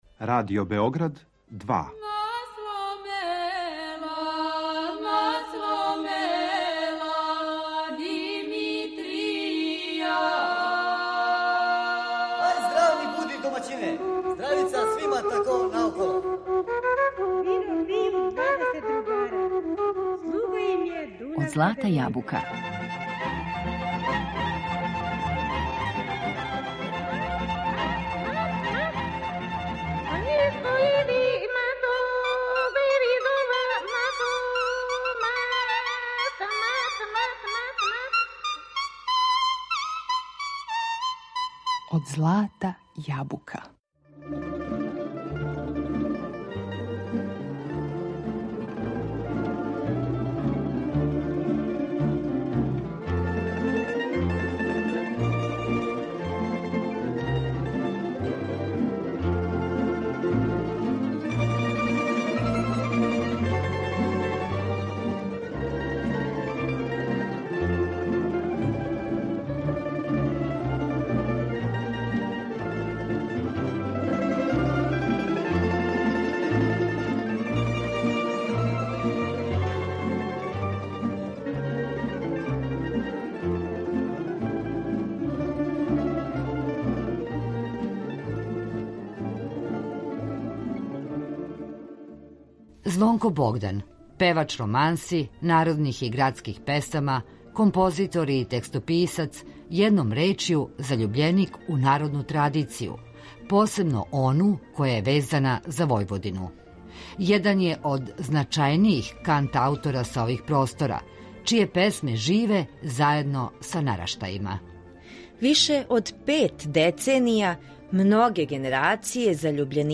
певач романси, народних и градских песама